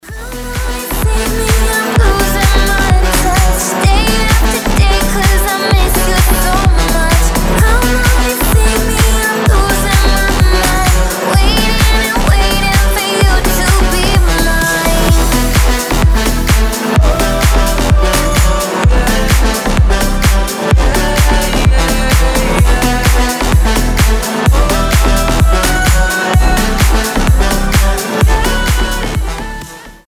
громкие
EDM
future house
красивый женский голос